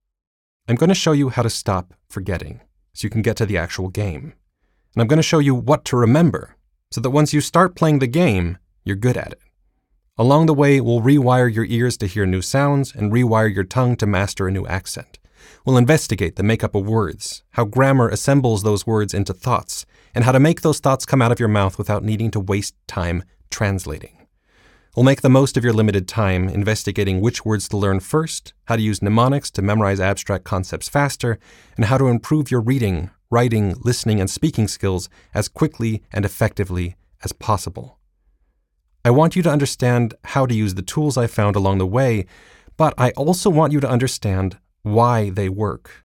audiobook-sample.m4a